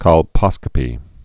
(kŏl-pŏskə-pē)